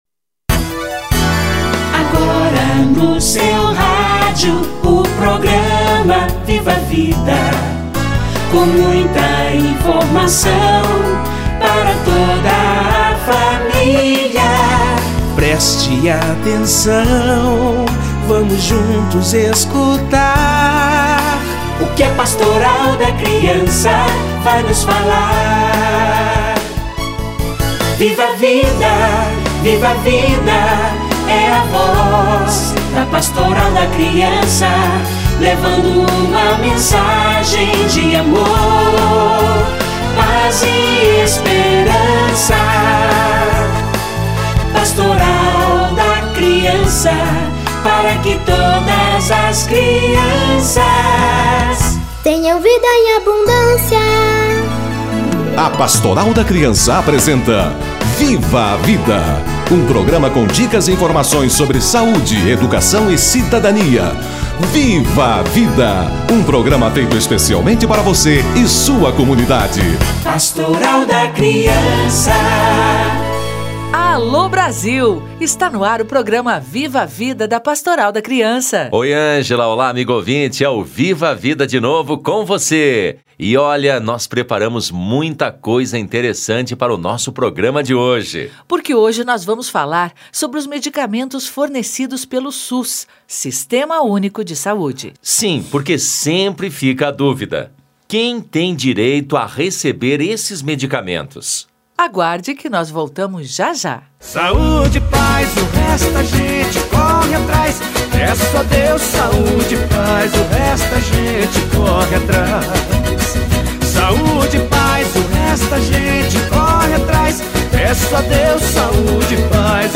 Medicamentos e suplementos: conheça seus direitos - Entrevista